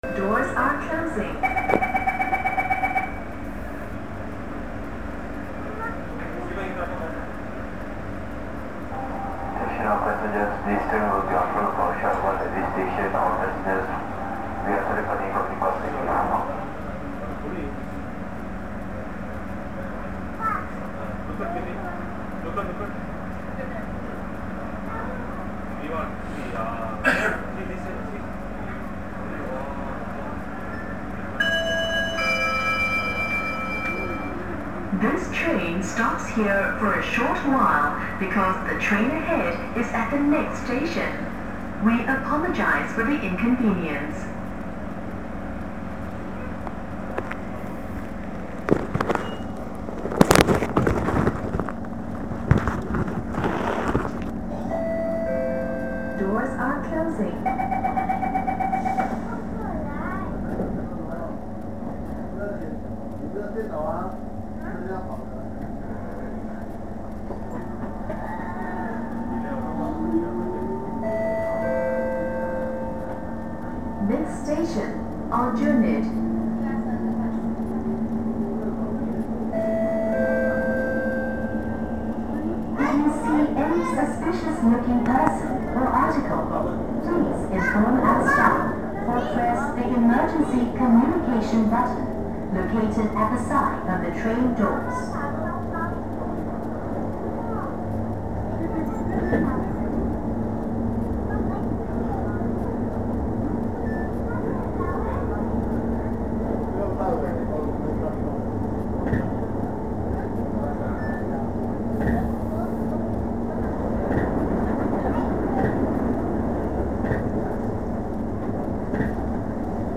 Kallang駅でドアが閉まるか・・と思えば、ドア閉めがキャンセルされて案内放送が入って少々停車。
車内放送を一部録音してみました。